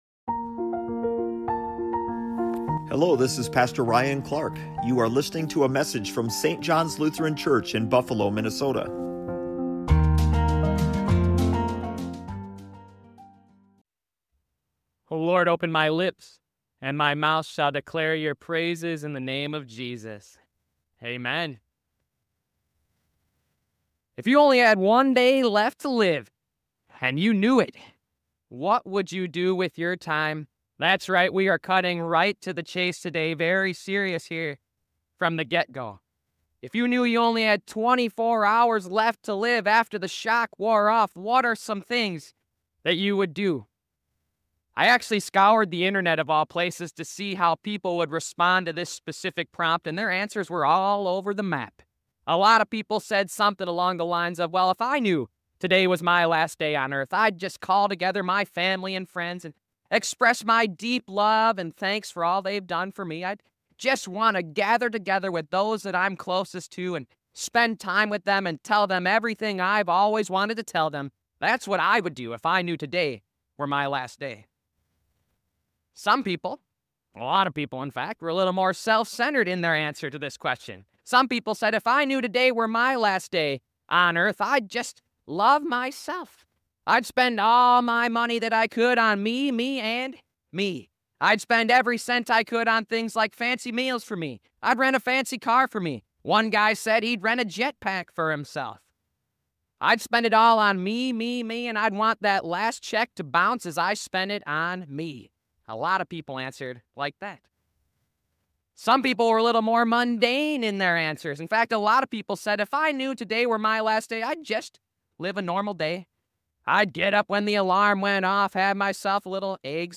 📖 Give this message a listen to discover the power of living with purpose in sermon 7 of the series "Made for CommUNITY".